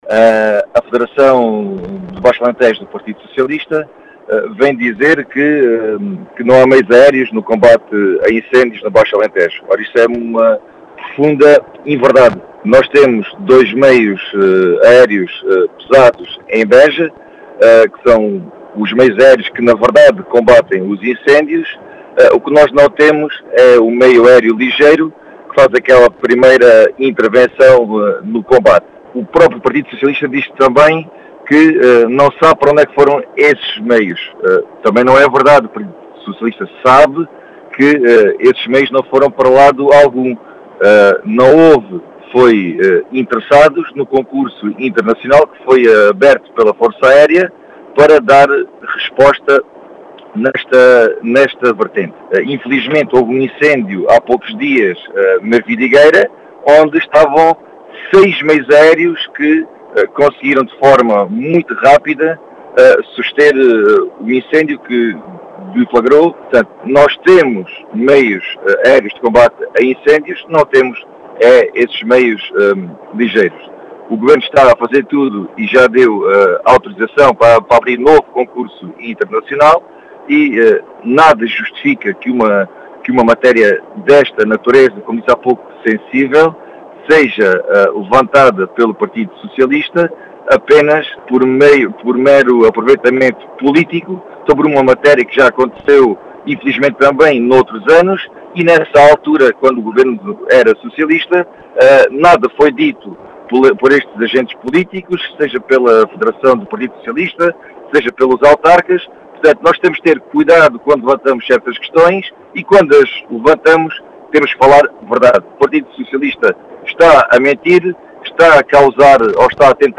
As explicações foram deixadas na Rádio Vidigueira, pelo deputado da AD eleito por Beja, Gonçalo Valente, que fala em “mero aproveitamento politíco”.